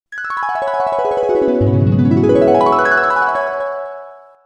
Звуки волшебства
• Качество: высокое
Звук волшебного превращения